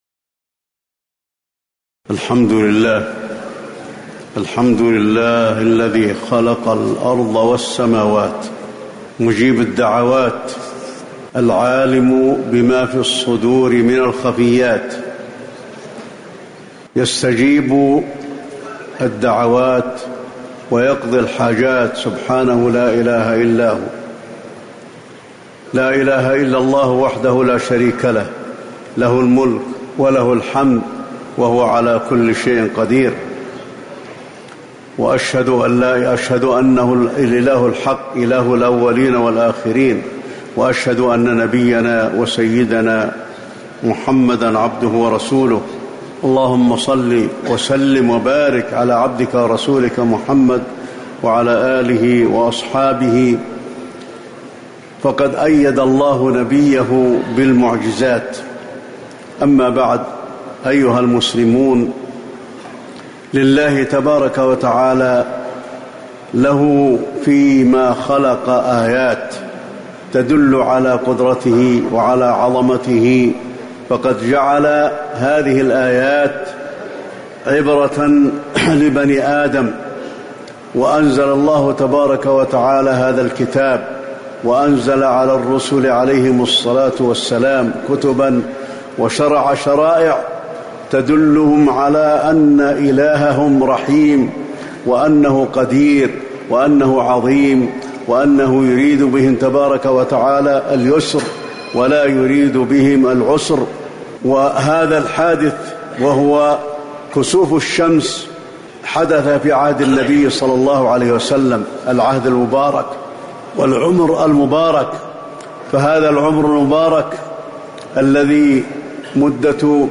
خطبة الكسوف المدينة - الشيخ علي الحذيفي
تاريخ النشر ٢٩ ربيع الثاني ١٤٤١ هـ المكان: المسجد النبوي الشيخ: فضيلة الشيخ د. علي بن عبدالرحمن الحذيفي فضيلة الشيخ د. علي بن عبدالرحمن الحذيفي خطبة الكسوف المدينة - الشيخ علي الحذيفي